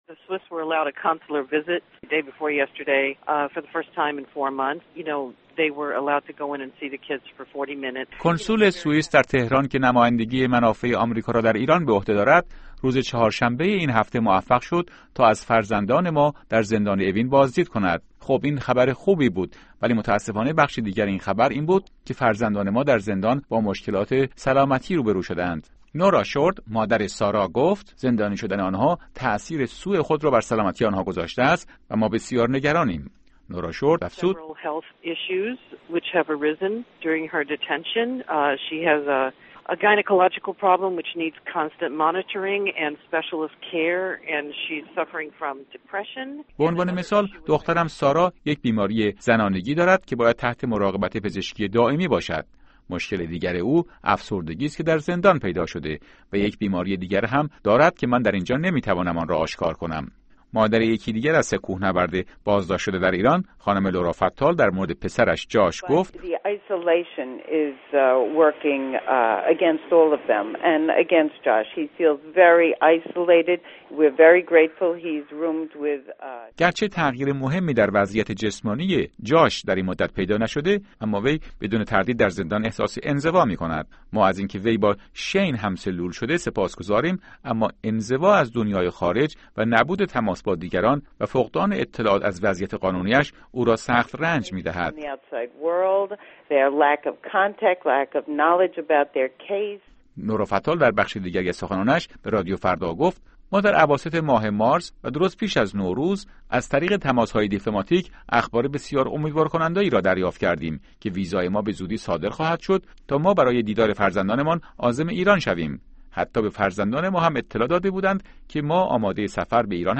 گفت‌وگو با مادران سه کوهنورد آمریکایی